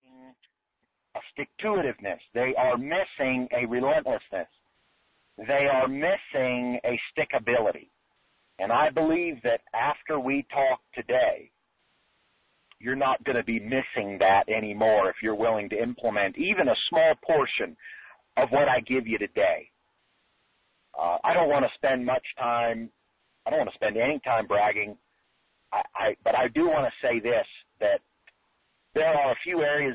Brand New LIVE Training: Discover the Secret to Overcoming Stalled Success and Become Ruthlessly Relentless in Your Business and Life.